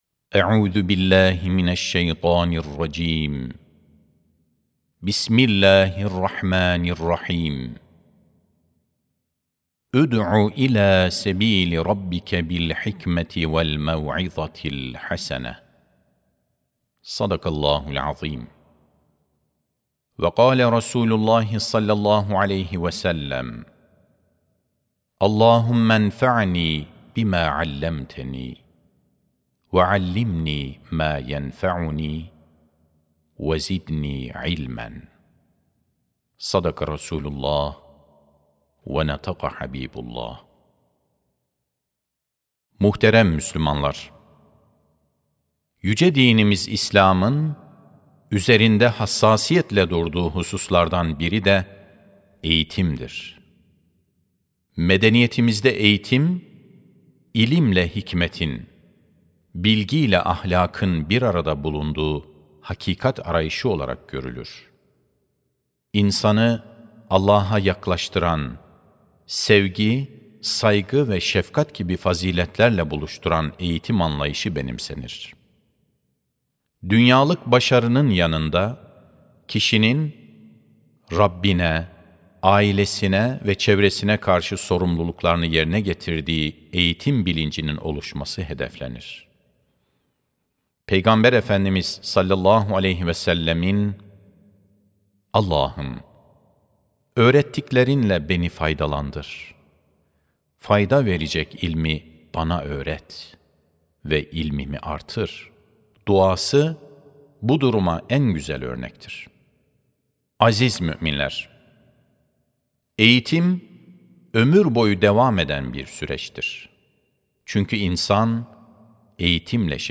24 Nisan 2026 - Cuma Hutbesi
Sesli Hutbe (Merhamet Eğitimi).mp3